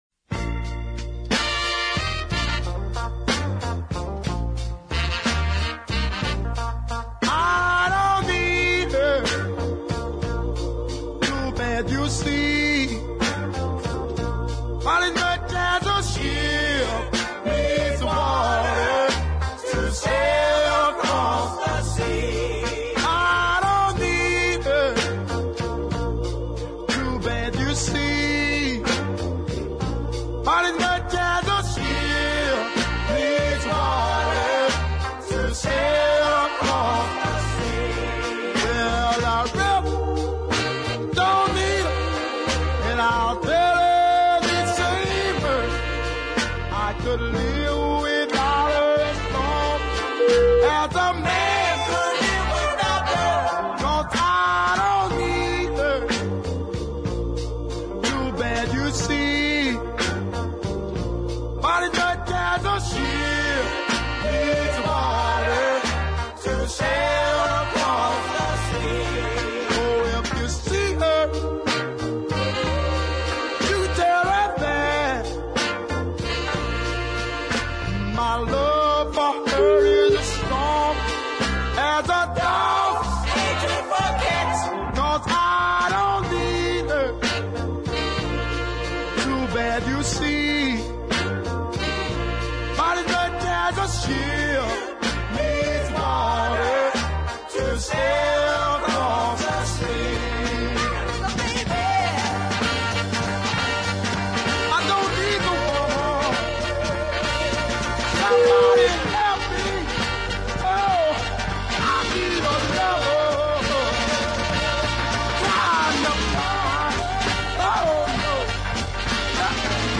strong deep ballads
with good horns and a fine female chorus.